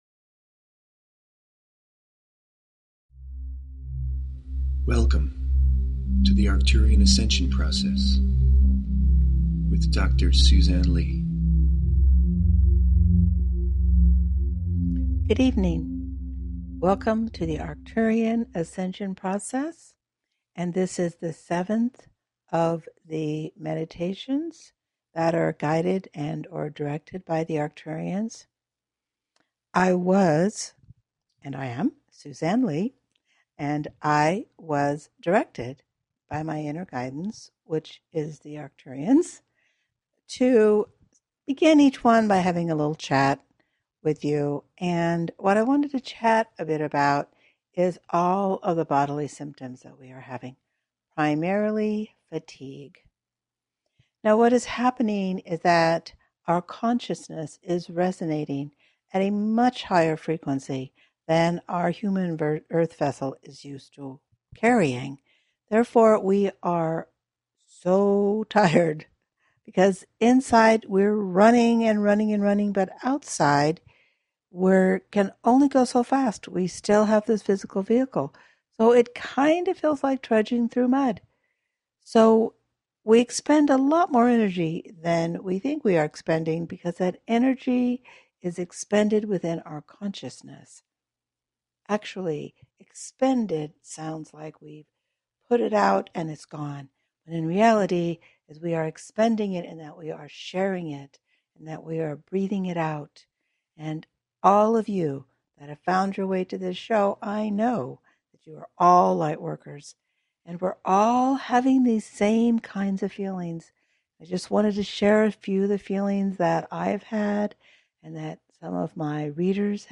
Talk Show Episode, Audio Podcast, Arcturian_Ascension_Process and Courtesy of BBS Radio on , show guests , about , categorized as